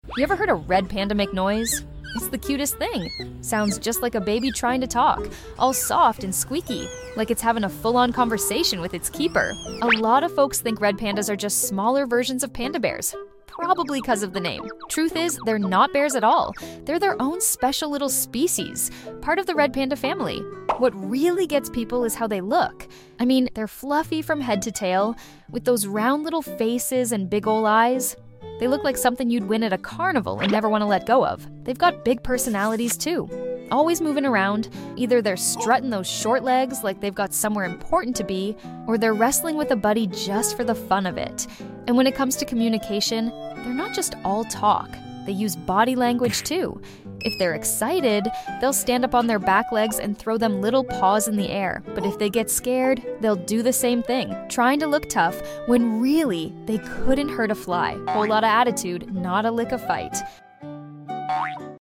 Red Panda Sounds Like a sound effects free download
Red Panda Sounds Like a Baby?Just Wait Till You Hear It